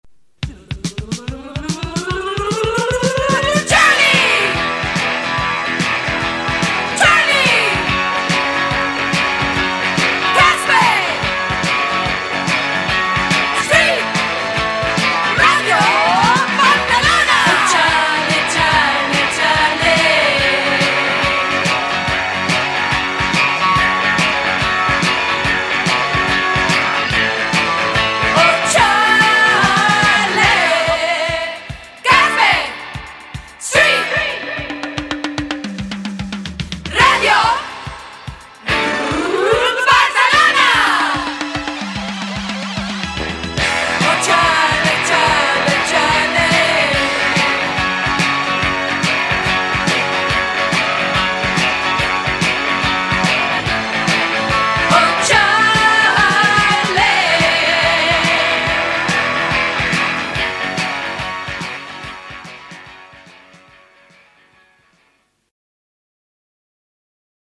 "Jingle" identificador del programa